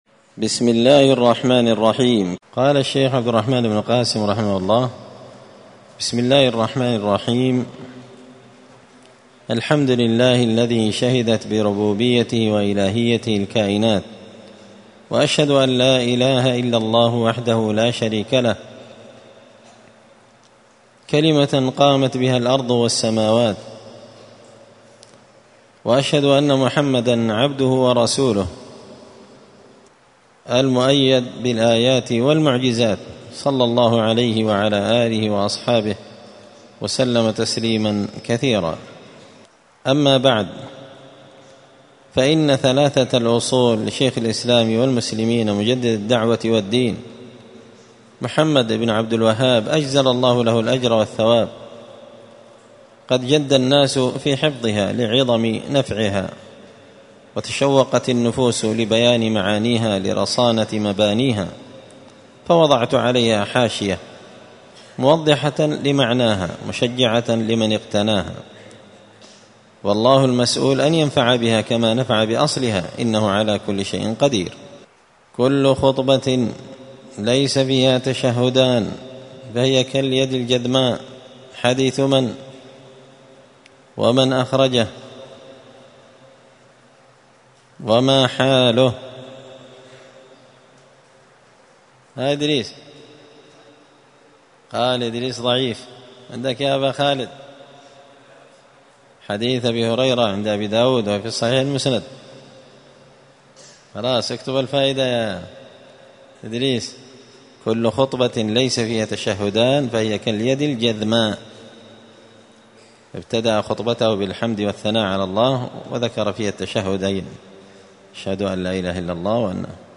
الأربعاء 26 ربيع الأول 1445 هــــ | الدروس، حاشية الأصول الثلاثة لابن قاسم الحنبلي، دروس التوحيد و العقيدة | شارك بتعليقك | 73 المشاهدات